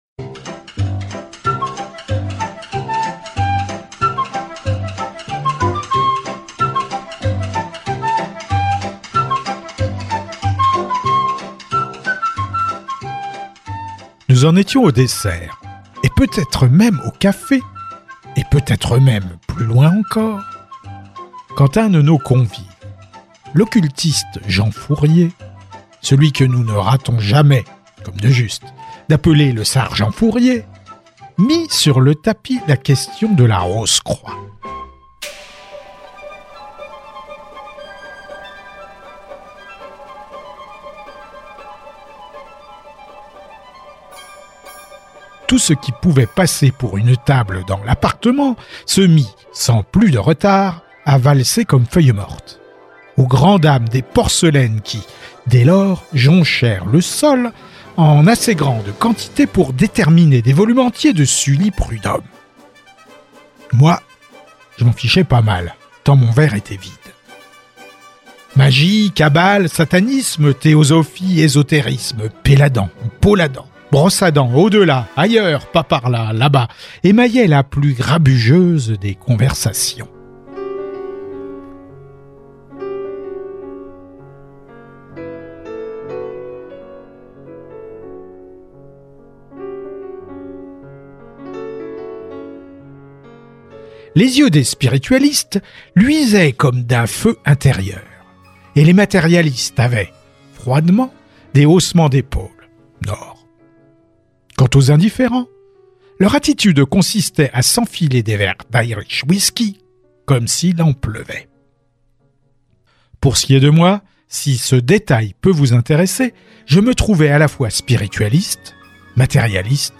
🎧 Dans la peau d'un autre & Scientia liberatrix, ou la belle-mère explosible – Alphonse Allais - Radiobook